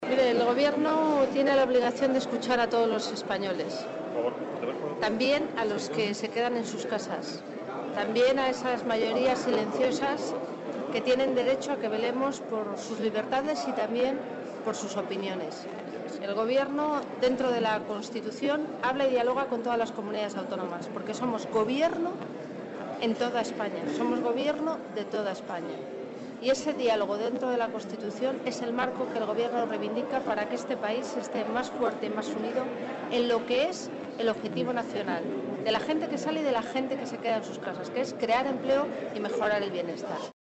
"El Gobierno, dentro de la Constitución, habla y dialoga con todas las Comunidades Autónomas porque somos Gobierno en toda España, de toda España", ha afirmado Sáenz de Santamaría en declaraciones a los periodistas al ser preguntada sobre si el Gobierno tiene intención de escuchar a los cientos de miles de catalanes que reclamaron la independencia de Cataluña durante las celebraciones de la Diada.